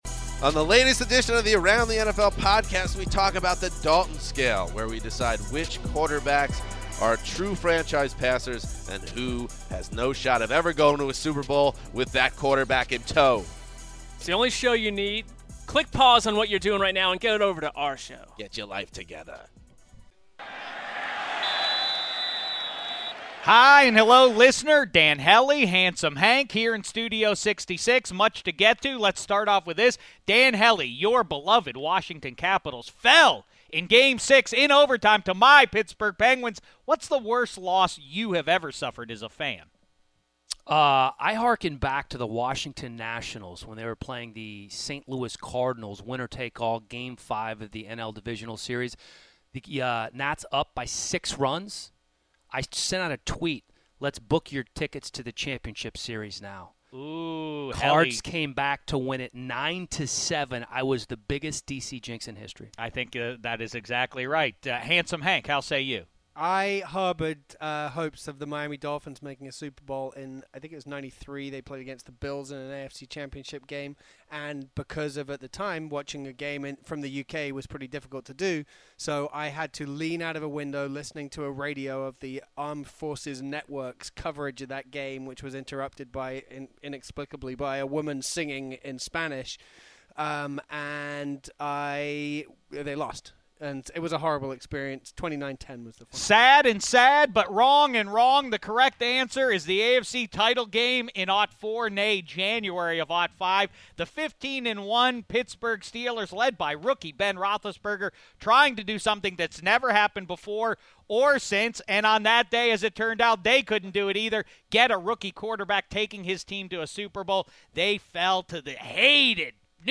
Shek then talks to Kyle Long about Jay Cutler's demeanor and Game of Thrones. The guys wrap by playing a surprise game show and "A moment of Pigzkin".